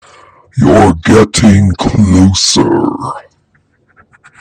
As I walk along the road, off to my left I hear a strange voice.
Old Man 1.mp3